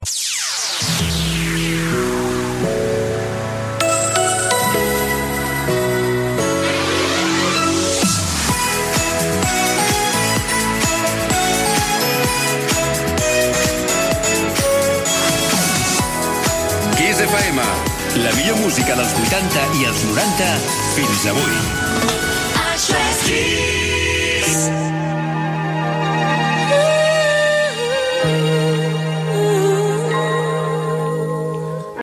Indicatiu de l'emissora a Barcelona